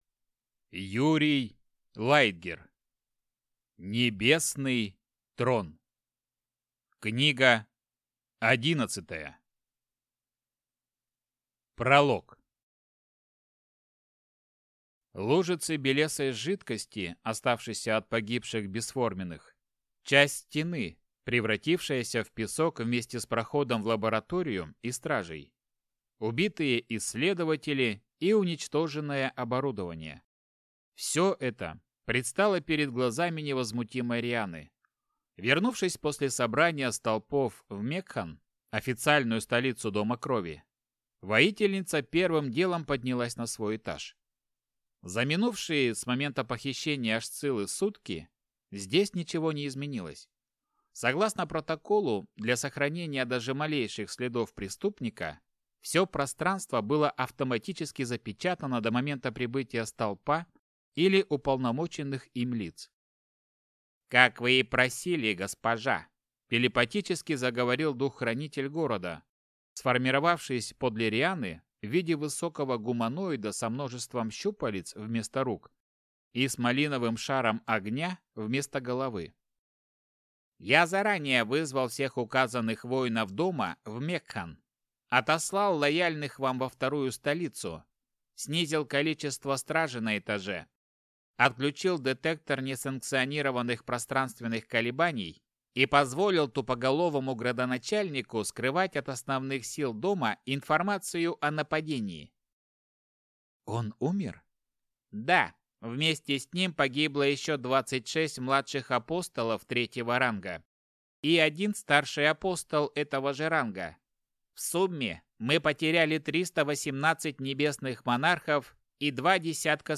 Аудиокнига Небесный Трон. Книга 11. Часть 1 | Библиотека аудиокниг